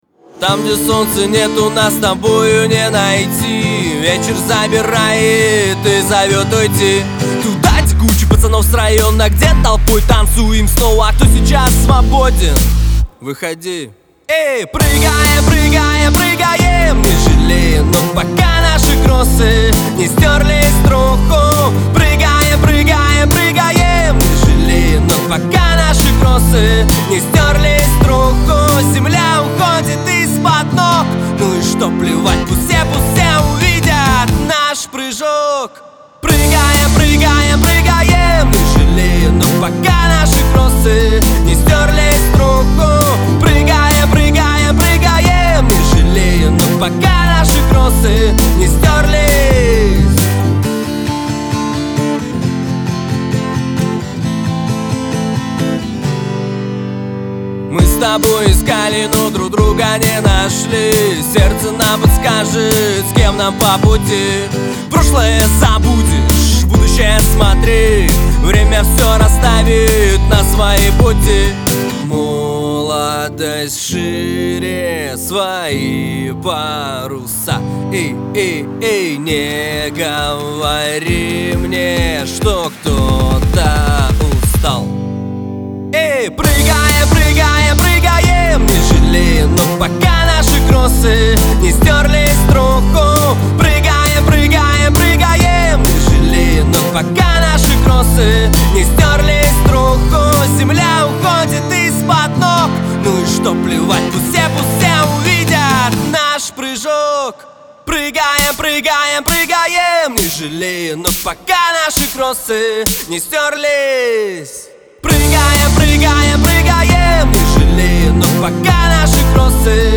это зажигательный трек в жанре поп-рок